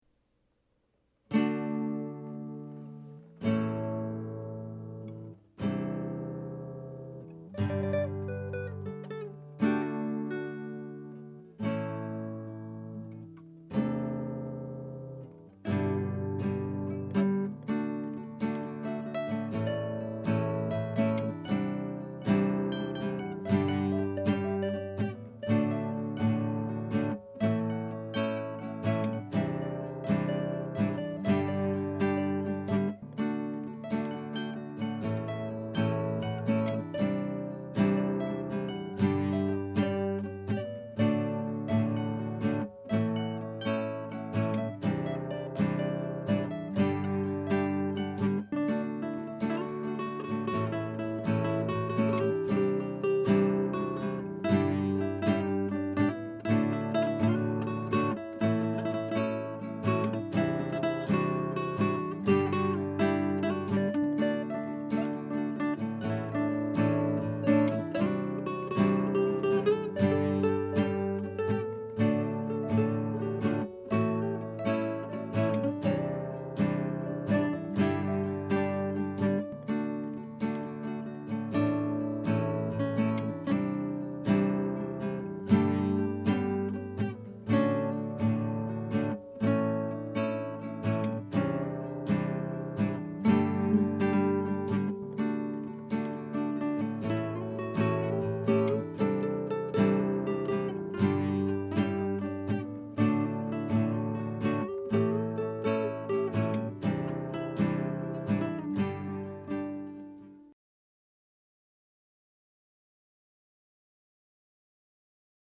(Instrumental)